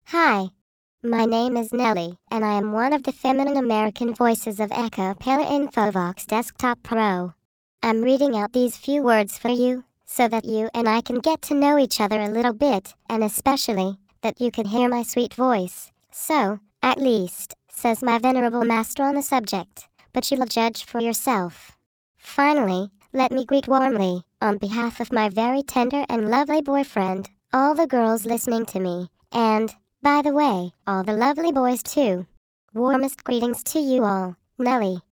Texte de démonstration lu par Nelly, voix féminine américaine d'Acapela Infovox Desktop Pro
Écouter la démonstration de Nelly, voix féminine américaine d'Acapela Infovox Desktop Pro